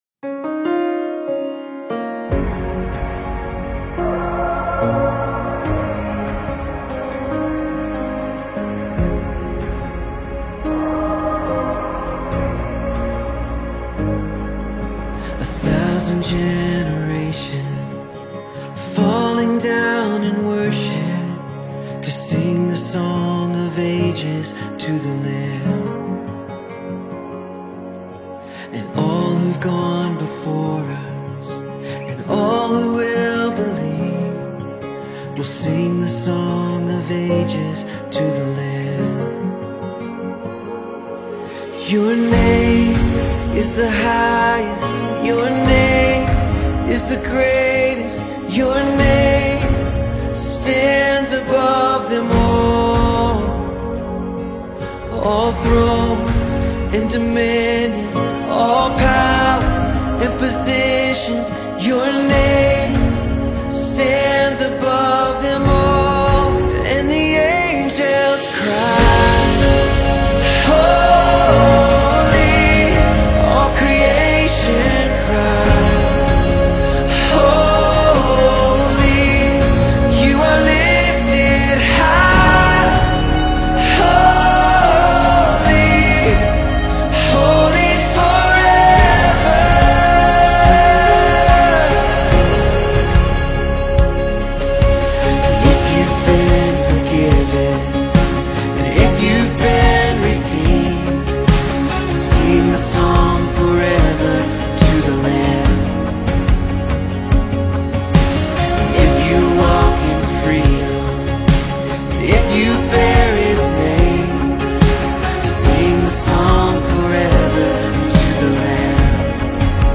a powerful worship anthem
Gospel Songs